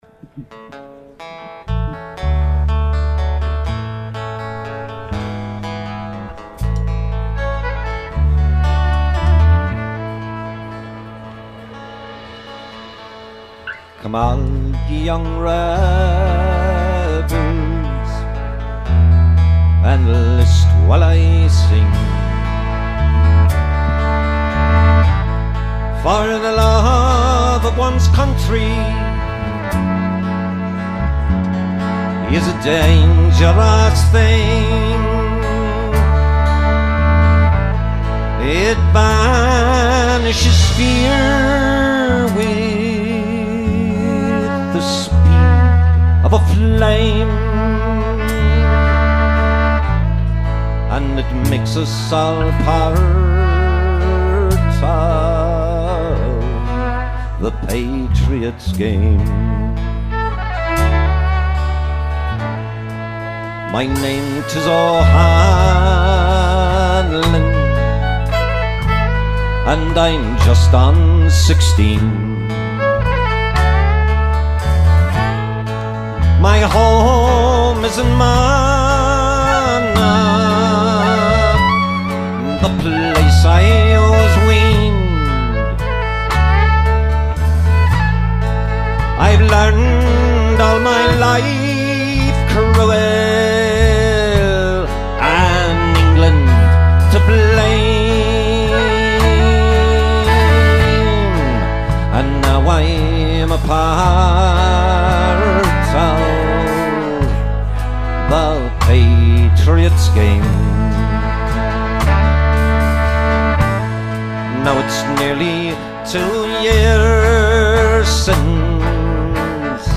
Recorded 3/15/06 at The Harp in North Amherst, MA